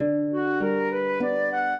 flute-harp
minuet4-3.wav